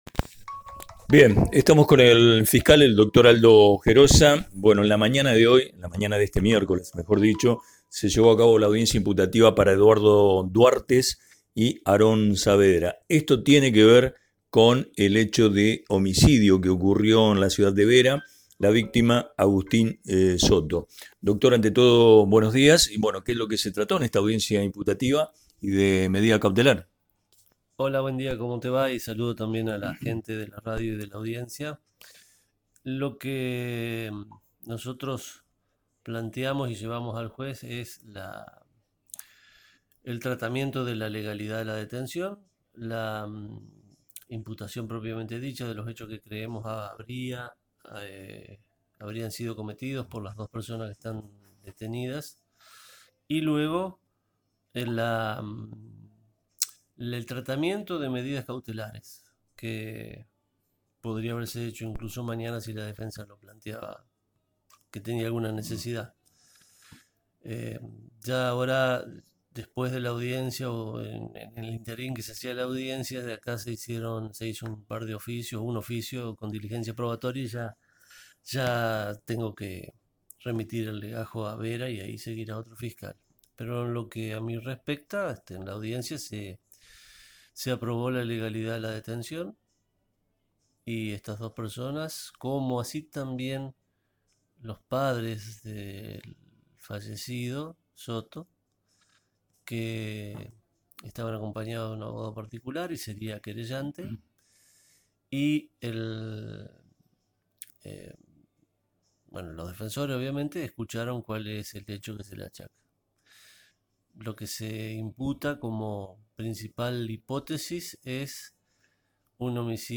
El Dr. Aldo Gerosa habló del caso tras la audiencia realizada este miércoles por zoom relacionado con el homicidio y dio detalles de cómo habría ocurrido el hecho.
Declaraciones-del-fiscal-Aldo-Gerosa-online-audio-converter.com_.ogg